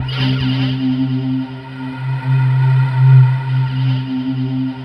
BLOWER.wav